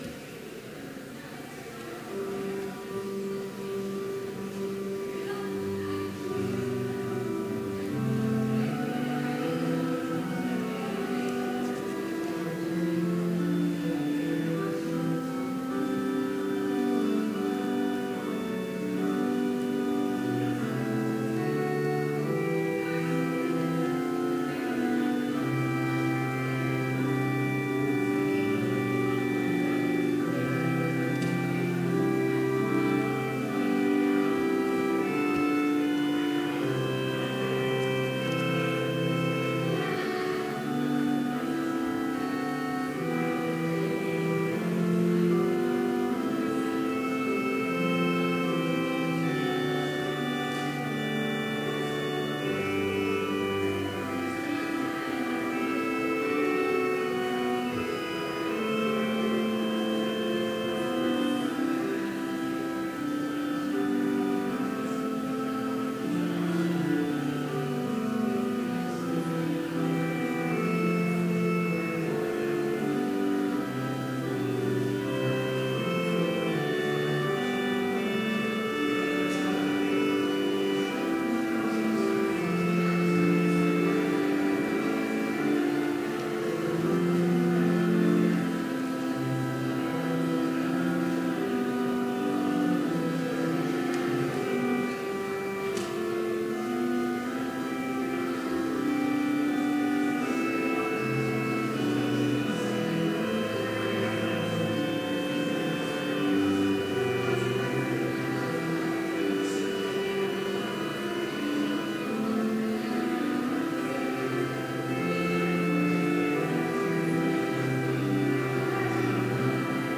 Complete service audio for Chapel - September 27, 2016